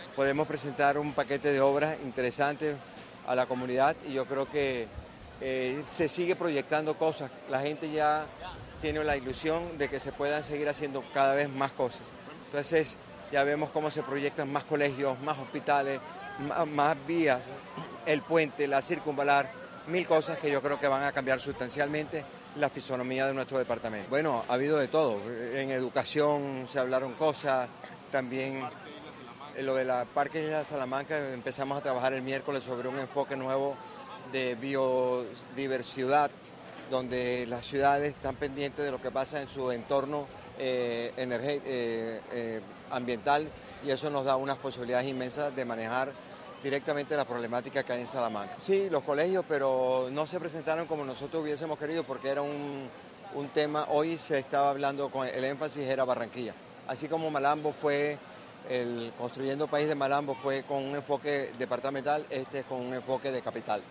El gobernador del Atlántico, Eduardo Verano De la Rosa, hizo parte del Taller Construyendo País que se realizó en el malecón junto al río Magdalena en la capital del Atlántico, donde hizo una presentación de las 400 obras que su administración ejecuta.